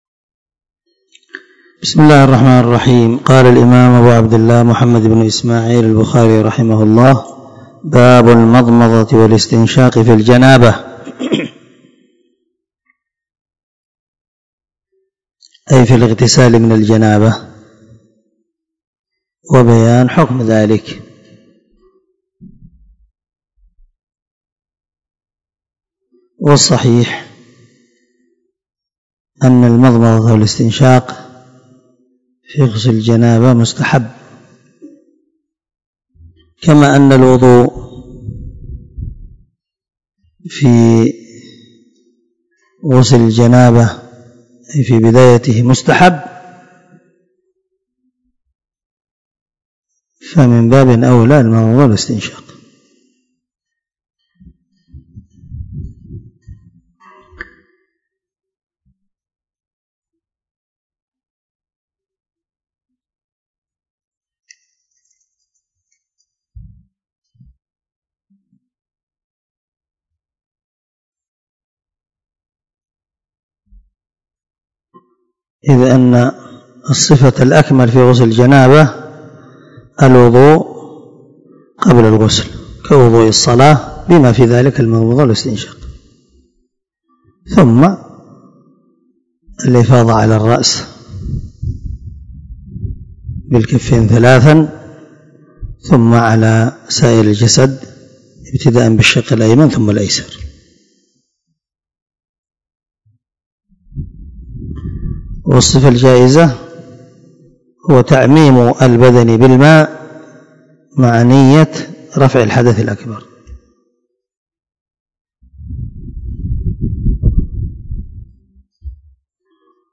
05كتاب الغسل من صحيح البخاري 218الدرس 11 من شرح كتاب الغسل حديث رقم ( 259 ) من صحيح البخاري .